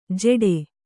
♪ jeḍe